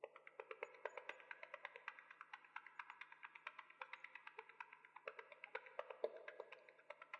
Звук движения муравья записан с помощью мощного микрофона